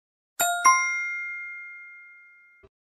jawaban-benar.wav